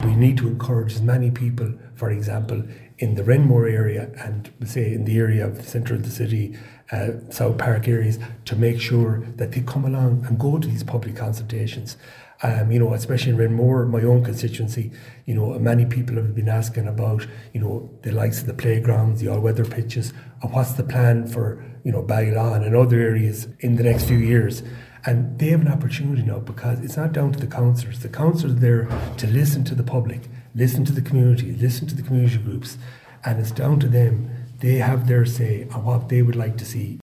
Councillor Alan Cheevers says it’s crucial these plans reflect the views of the community